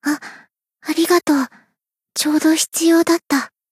BA_V_Hibiki_Cheerleader_Battle_Recovery_1.ogg